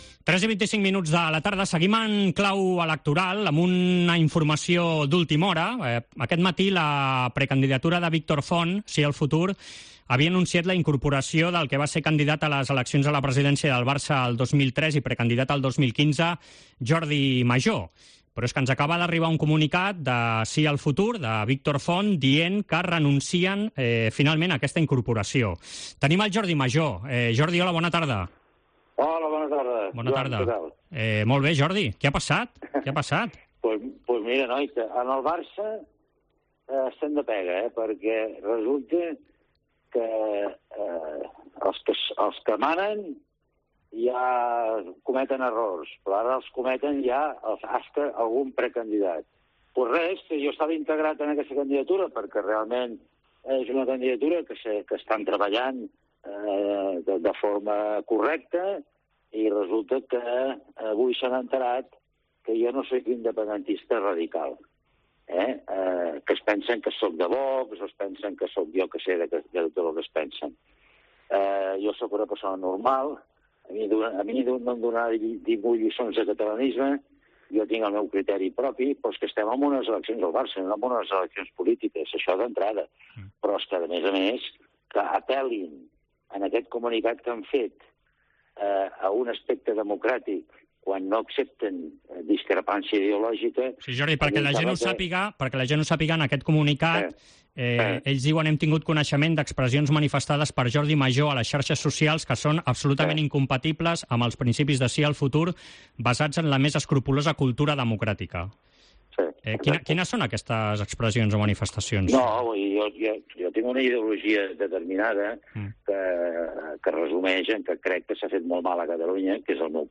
Entrevista en Esports COPE